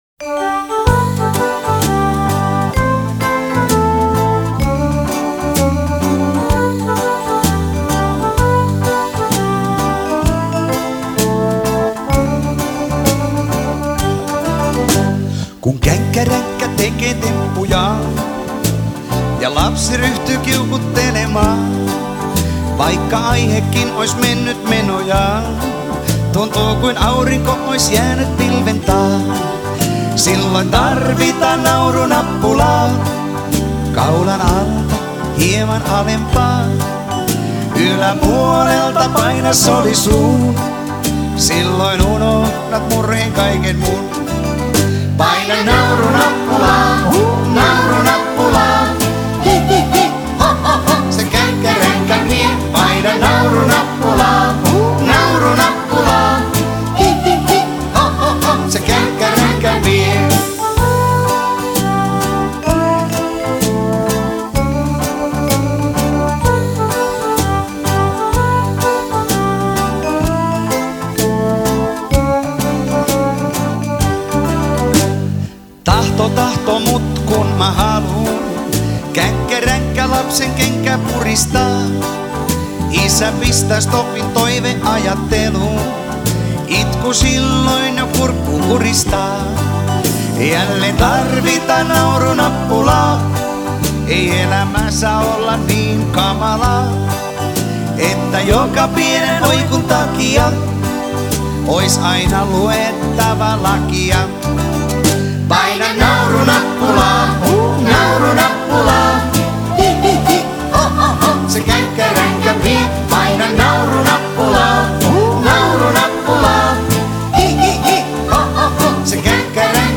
вот интересная версия детского смеха с репом